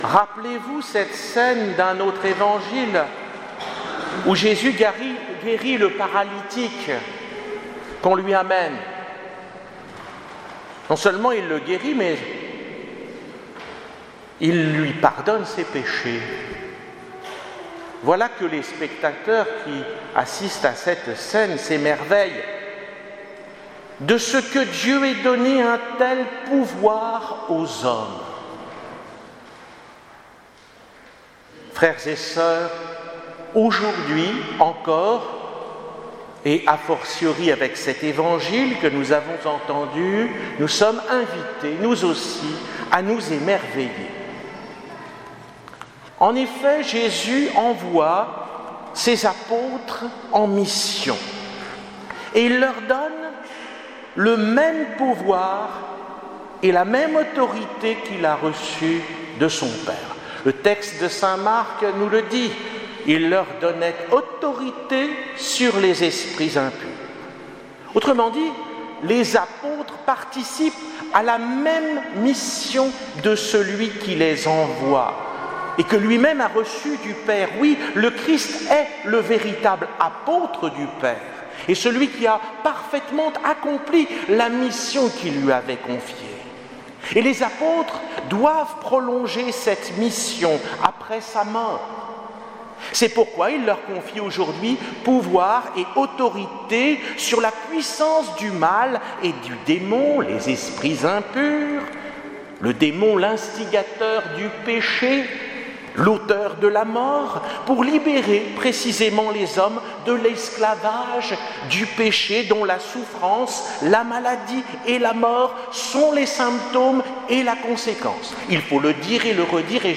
Homélie du 15ème dimanche du Temps Ordinaire 2018